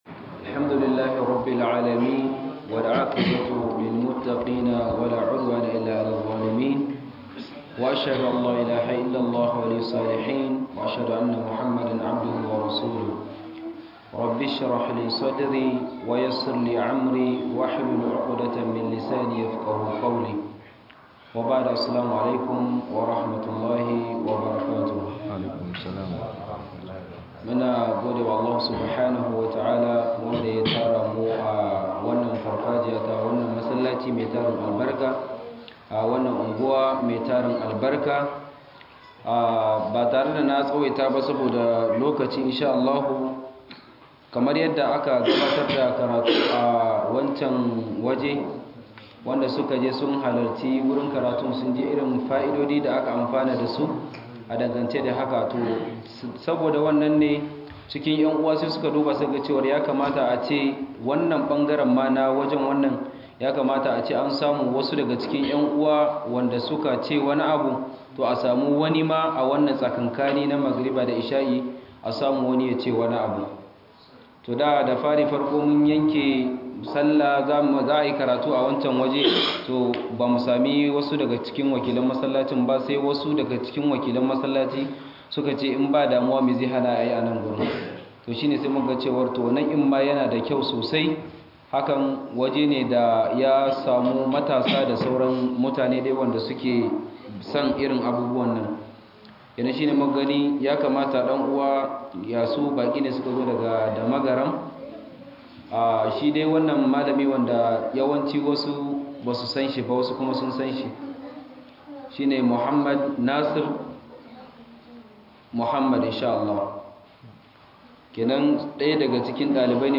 Ziyara bandé-2024 - Muhadara